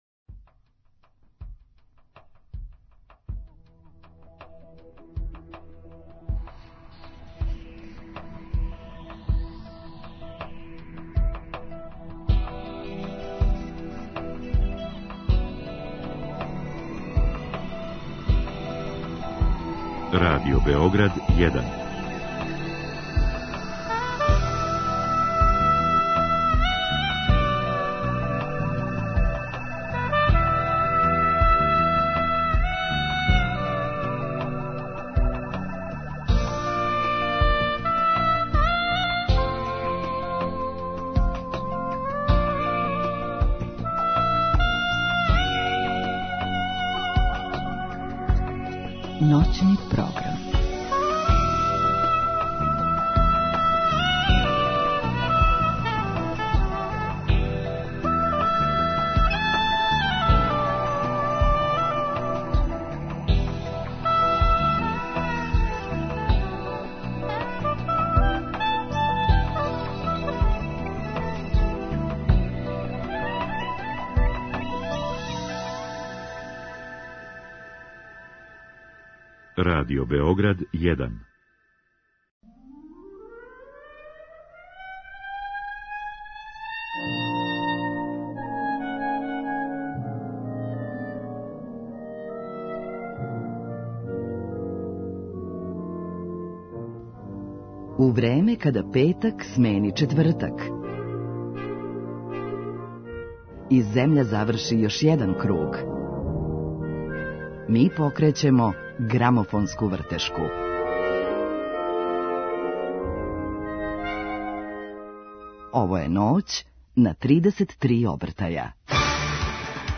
У емисији ће говорити, између осталог, о свом глумачком искуству, да ли је музика његов животни позив и жељи да едукује децу. Сазнаћемо како су биране песме за нови албум, а имаћемо прилике и да их преслушамо.